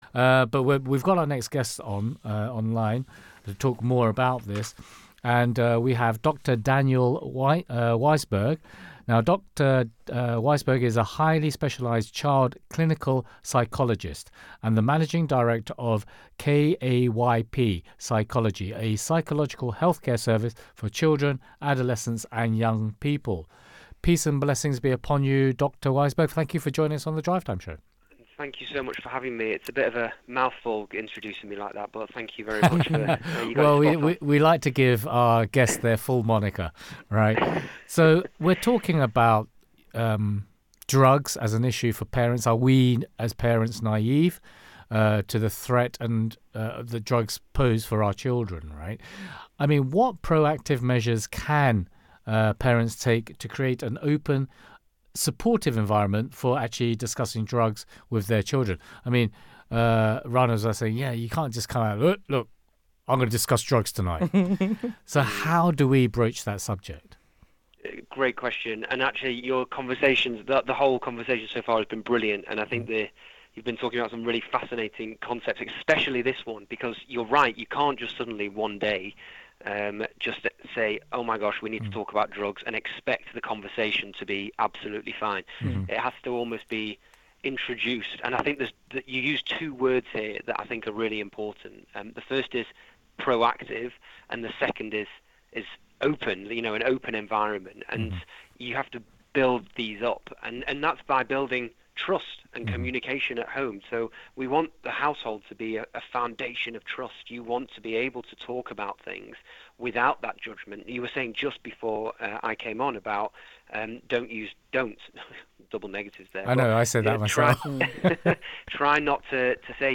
Voice-of-Islam-Drugs-Interview.mp3